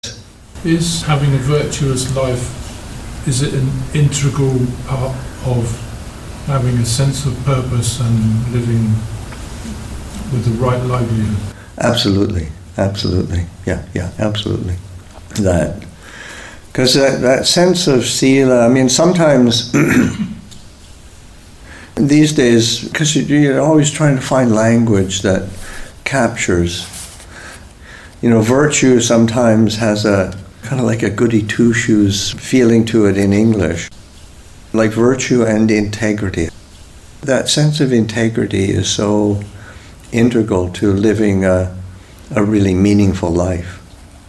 Readings from The Island, Session 27 – Feb. 16, 2025